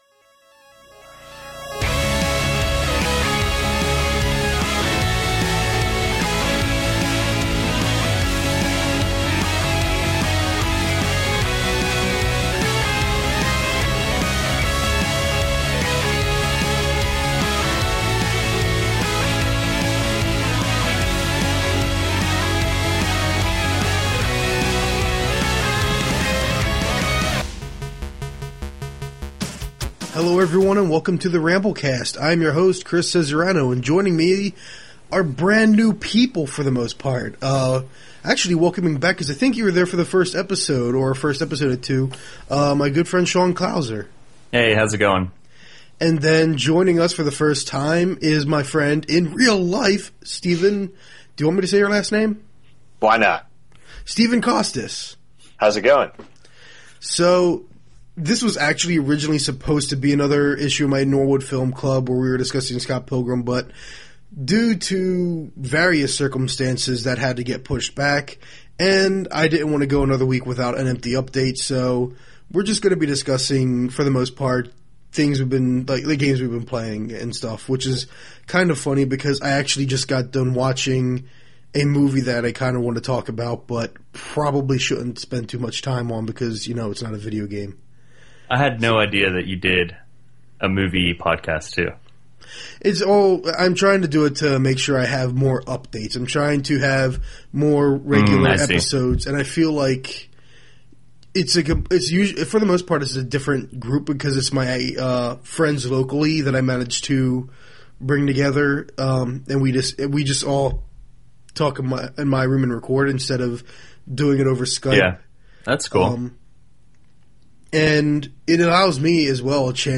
I must confess, when we recorded this I was getting sleepy and I am also at the early stages of illness, so I was feeling a bit worse for wear.